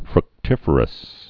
(frŭk-tĭfər-əs, frk-)